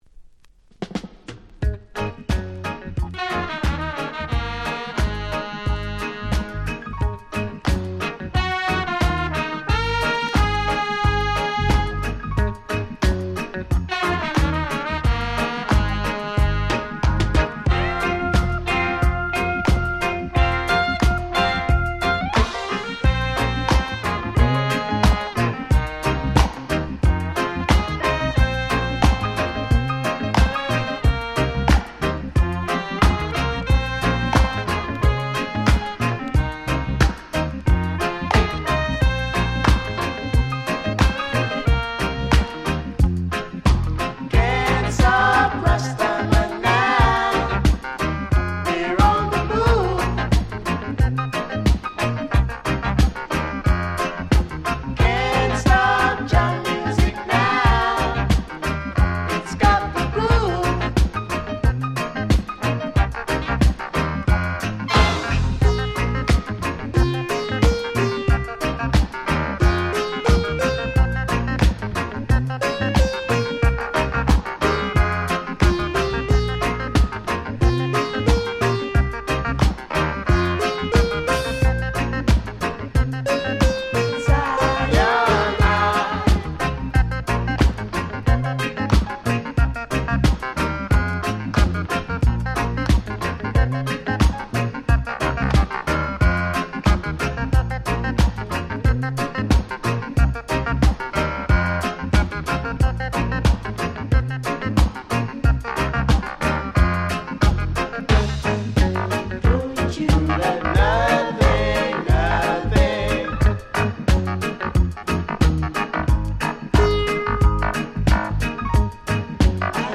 07' Nice Reggae Remix / Re-Edit !!